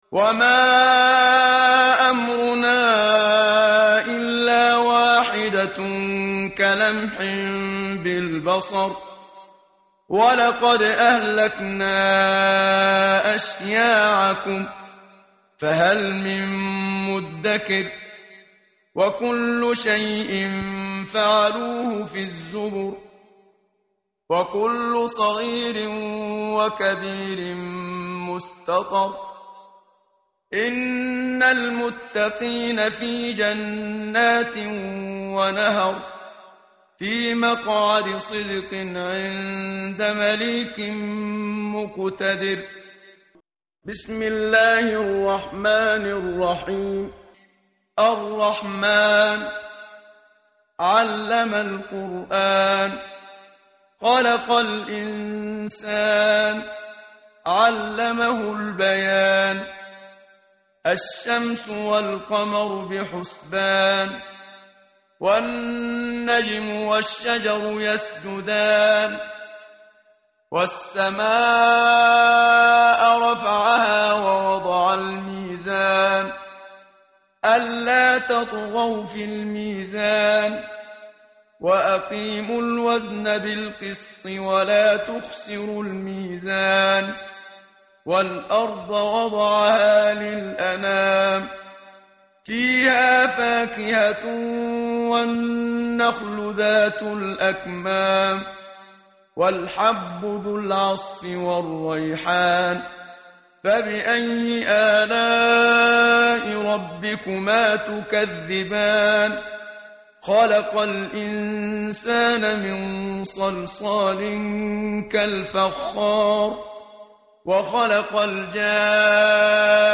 tartil_menshavi_page_531.mp3